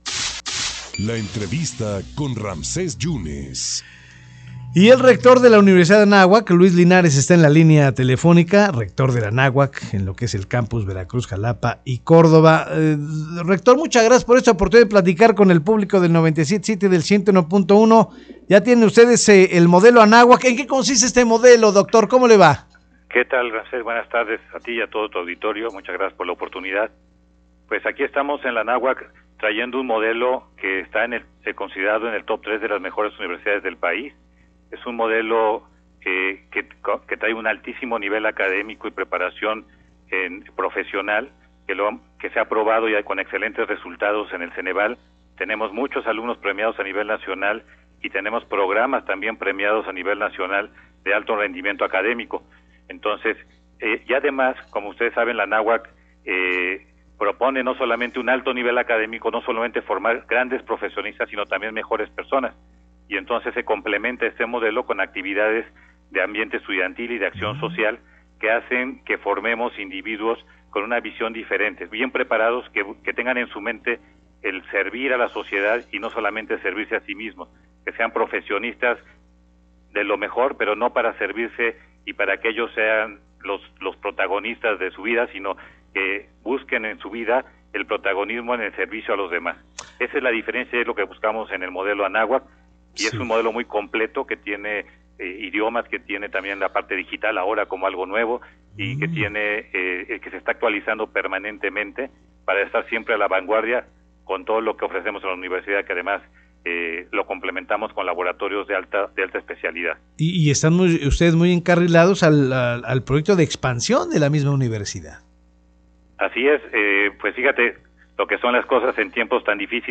en entrevista para En Contacto de Avanoticias.